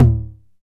Index of /90_sSampleCDs/Roland L-CD701/DRM_Drum Machine/KIT_TR-909 Kit
TOM 909 TO03.wav